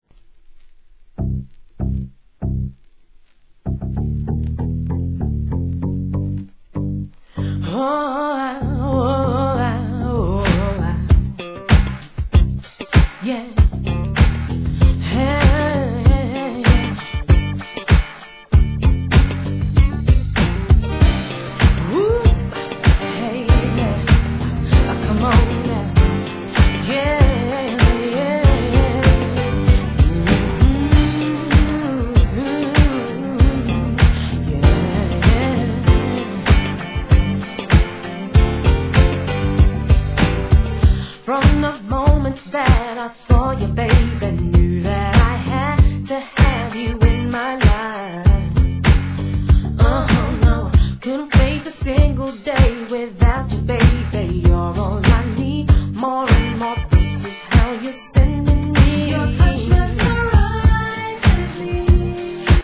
HIP HOP/R&B
美メロに乗せて歌う耳障りのイイ好ダンサブルR&Bでここ日本でも大人気!MIXでもお馴染みでしょう!!